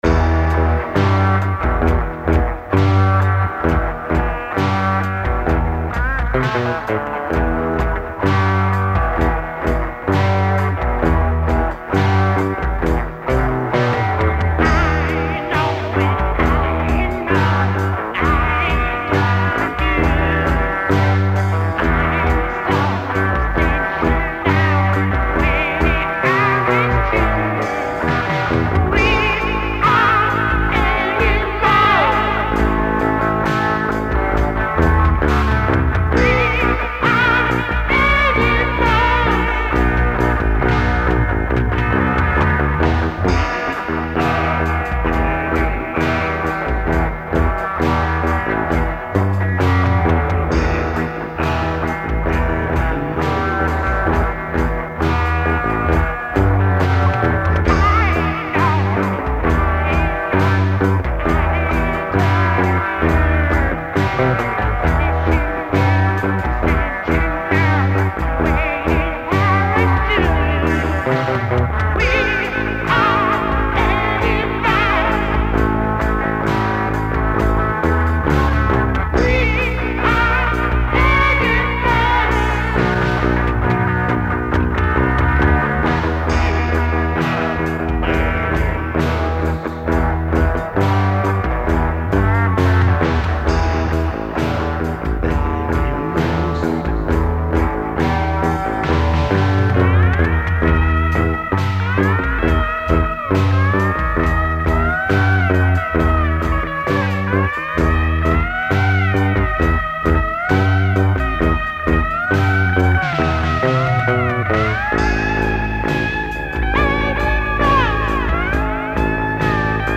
Сборник пробных записей, этюдов, репетиций
музыка, вокал (1, 2, 7, 9), гитары
барабаны, перкуссия
клавиши